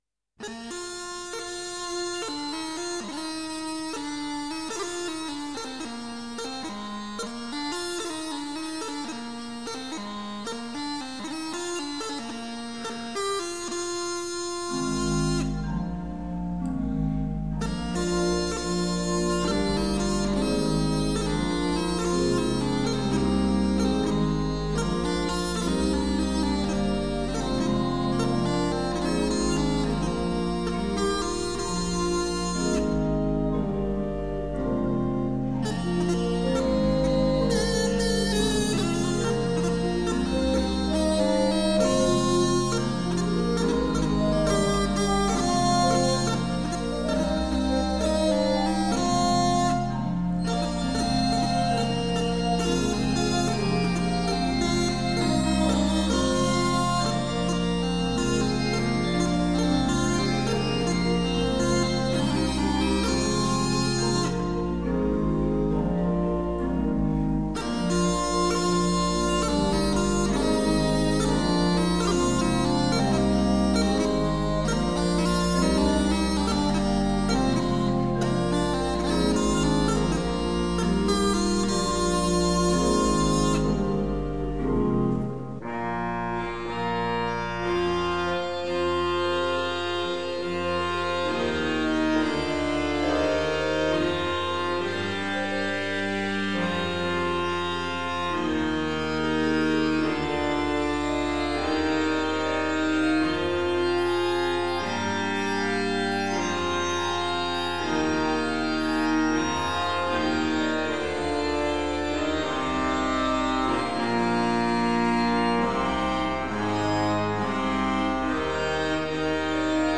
Orgel und Dudelsack...
mit Orgel und schottischer Panflöte...